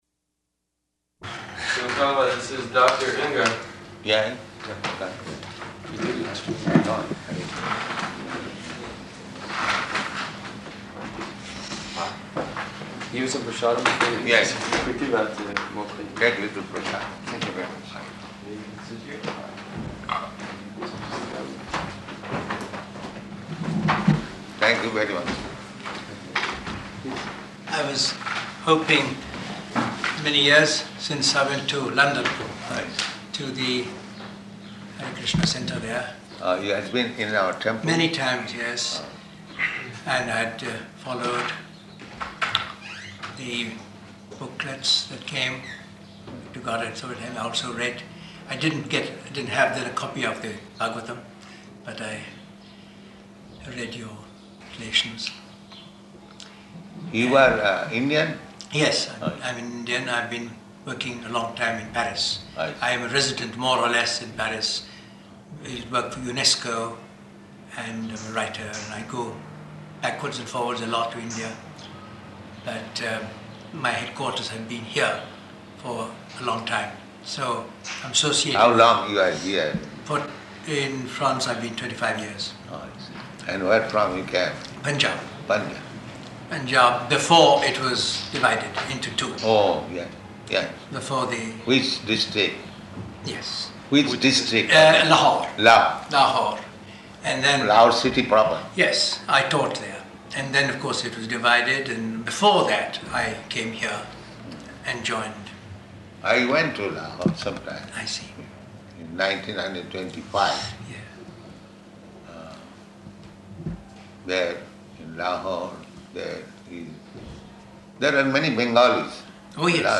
-- Type: Conversation Dated: August 10th 1973 Location: Paris Audio file